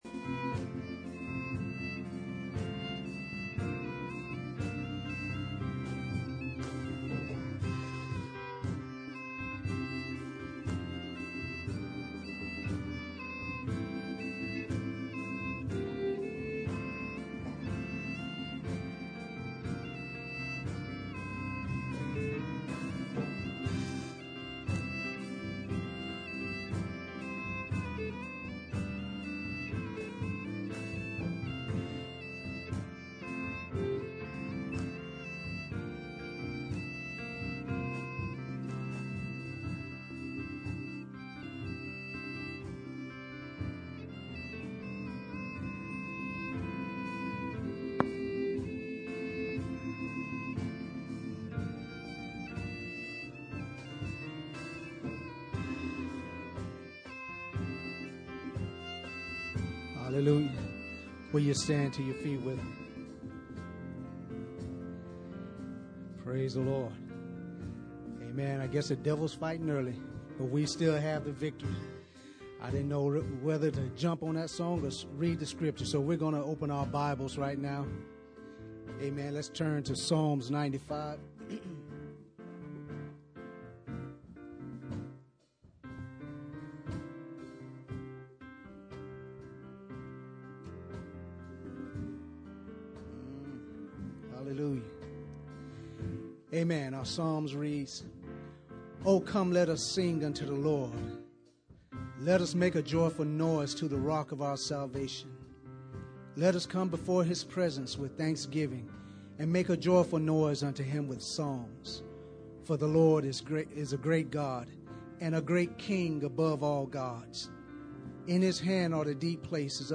Pastor Service Type: Sunday Morning %todo_render% « Study on Ephesians 2